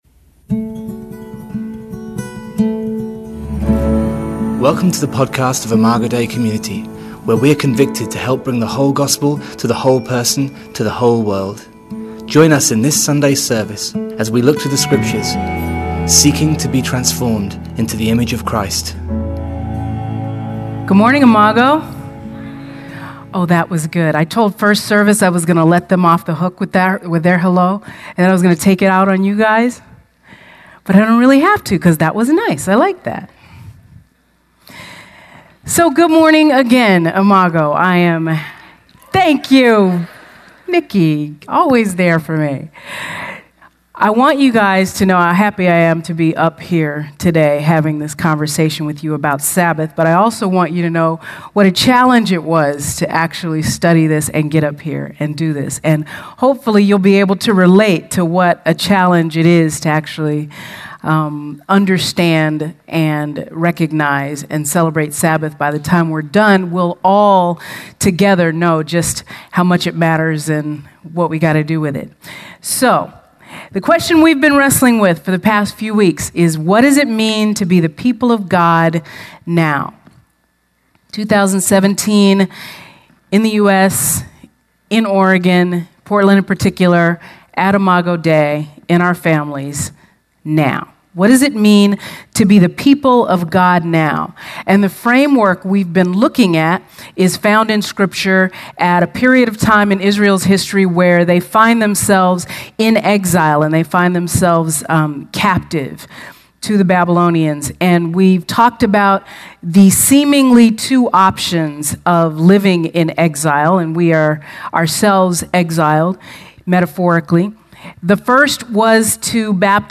This week's message focuses on sabbath and sacrament. Scripture Reference: John 6:25-59